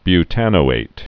(by-tănō-āt)